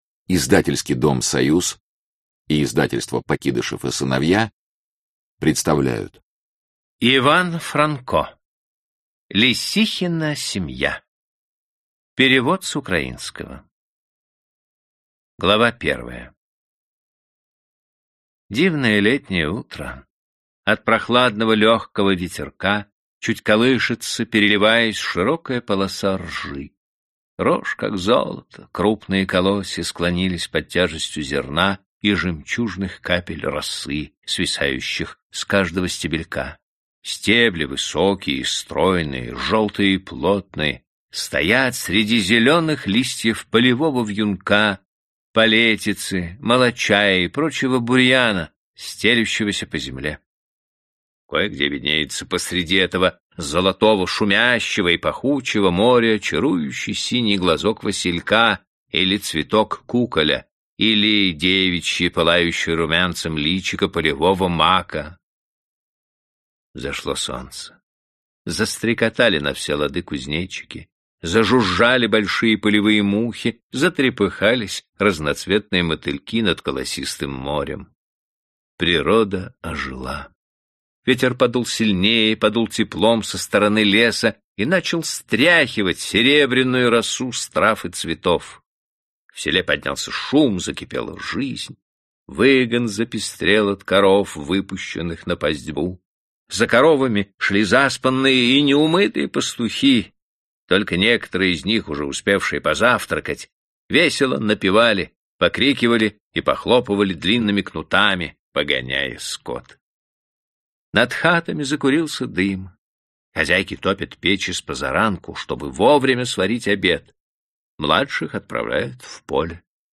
Аудиокнига Лесихина семья